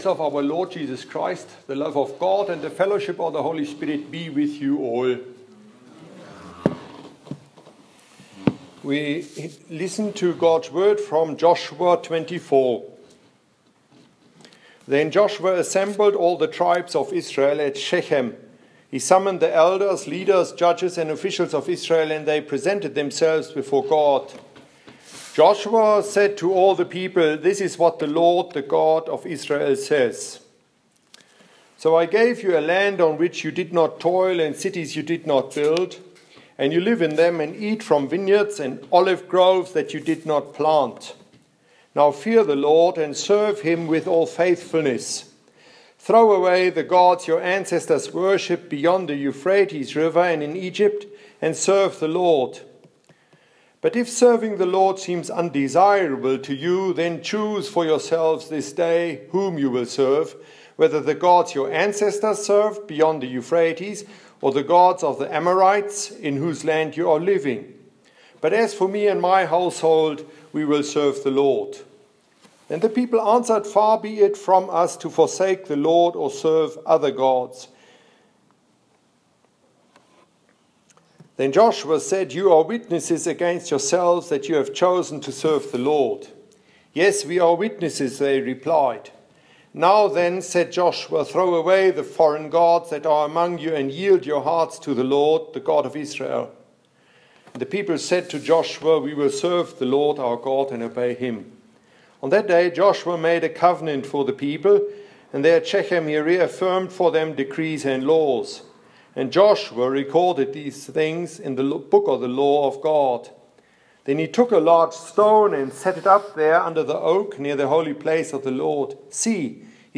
Sermon during Matins: Joshua 24